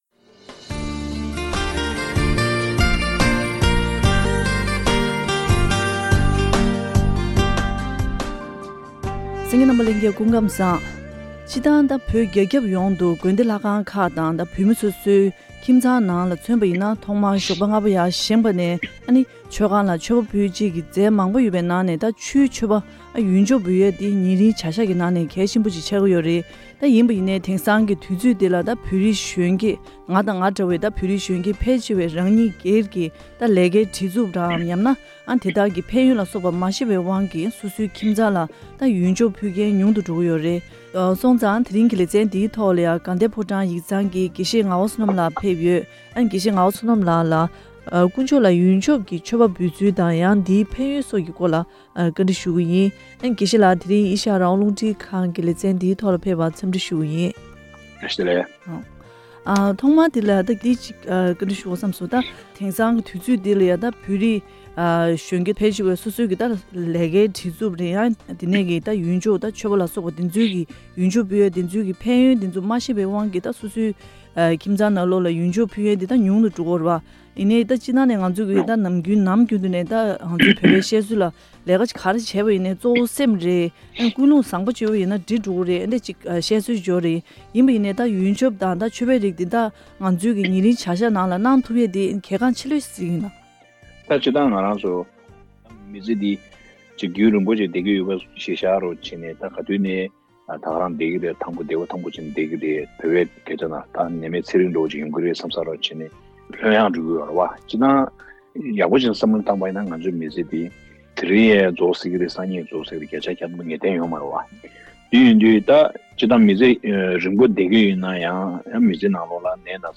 བྲེལ་ཟིང་ཆེ་བའི་དུས་རབས་ནང་དཀོན་མཆོག་ལ་མཆོད་པ་འབུལ་སྲོལ་སྤྱི་དང་། བྱེ་བྲག་ཏུ་ཡོན་ཆབ་འབུལ་བའི་སྲོལ་ལུགས་ལ་གཞོན་སྐྱེས་མི་རབས་ཀྱིས་འཇུག་ཚུལ་ཐད་བཀའ་འདྲི་ཞུས་པར་གསན་རོགས་གནང་།